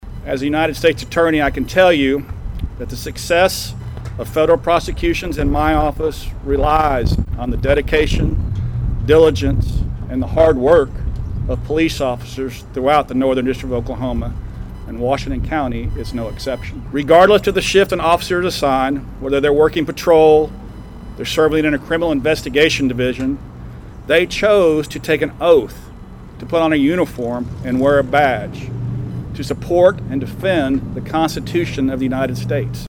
In a solemn ceremony outside of Bartlesville Police Headquarters, dozens of Washington County citizens gathered to honor law enforcement officers who have made the ultimate sacrifice.
The community recognized the peace officers who have died in the line of duty during Wednesday's National Police Week memorial ceremony. Clint Johnson, the U.S. Attorney for the Northern District of Oklahoma, reflected on when President John F. Kennedy officially designated the week to honor police back in 1962 and how it is still impactful today.
Clint Johnson on Service 5-14.mp3